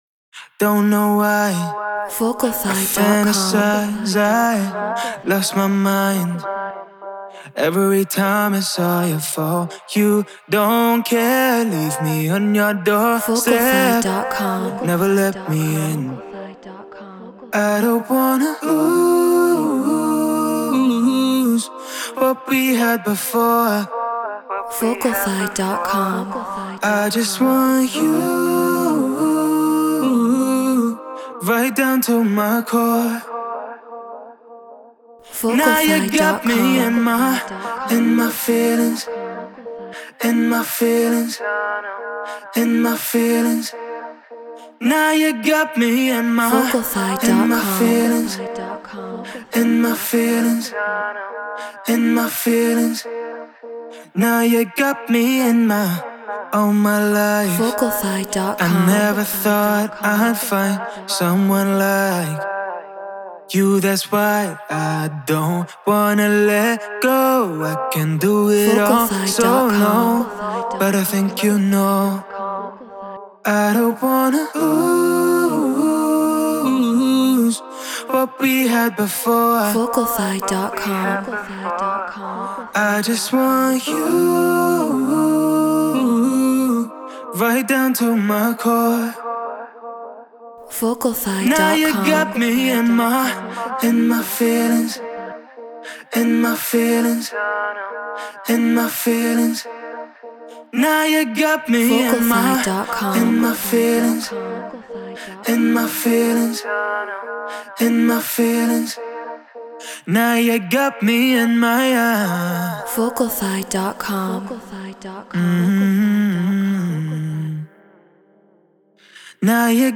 Pop 103 BPM G#maj
RØDE NT1-A Apollo Solo FL Studio Treated Room